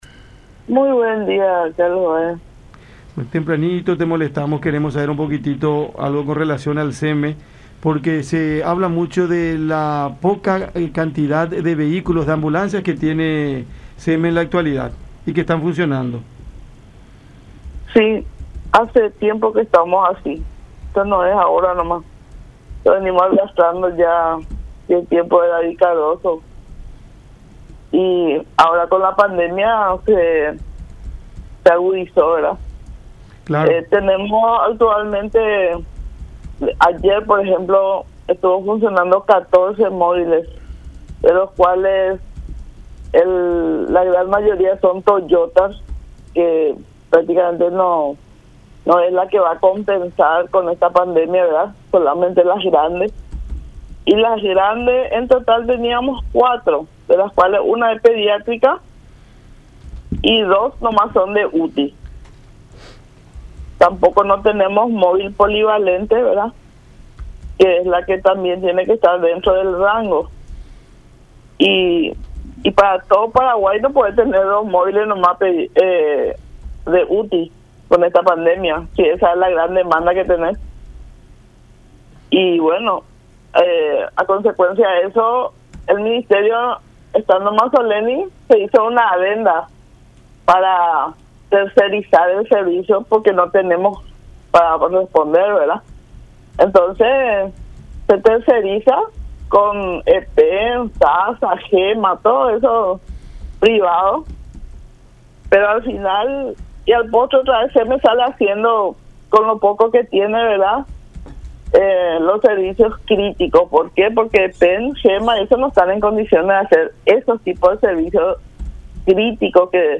en conversación con Cada Mañana por La Unión